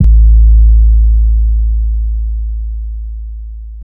tm siz 808 remastered.wav